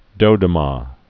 (dōdə-mä, -dō-)